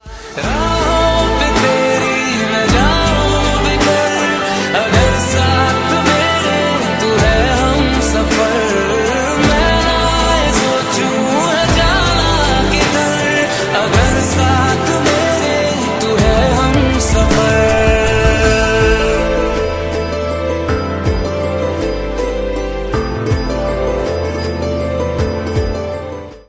Ringtone File
Bollywood Songs